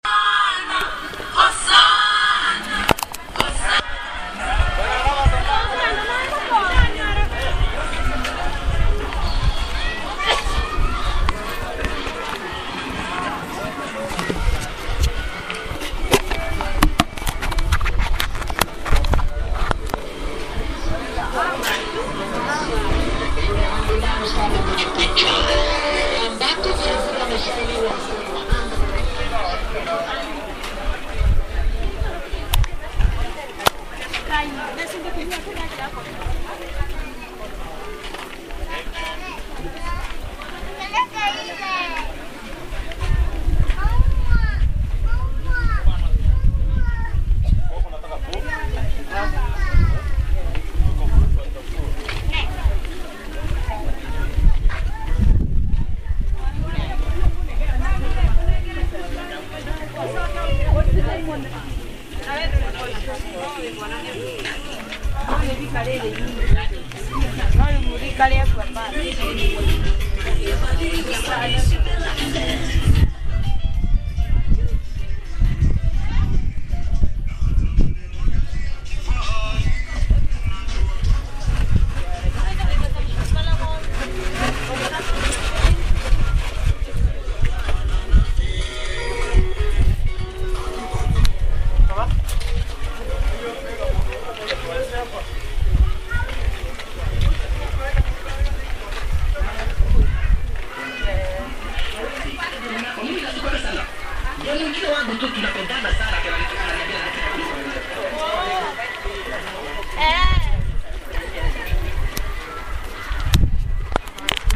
Walking in Mukuru